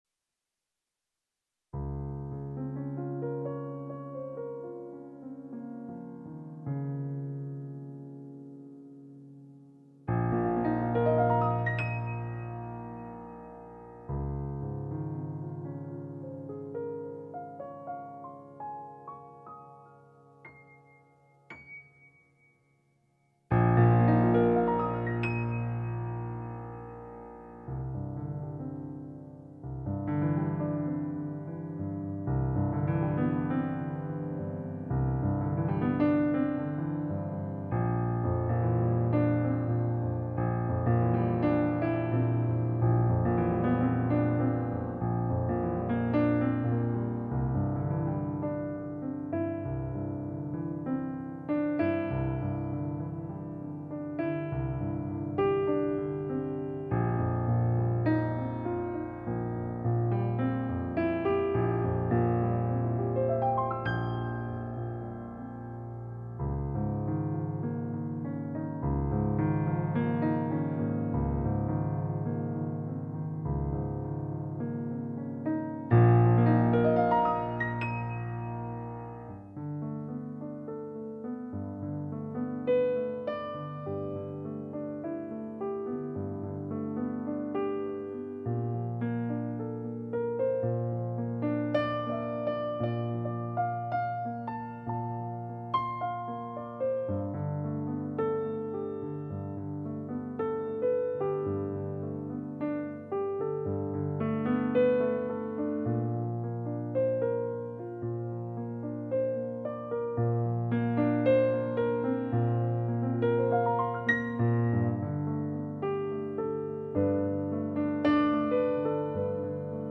*Use your headphones for better sound. 9th FeelYourself Download If you enjoyed this session, please like, comment, and subscribe to my channel for more music.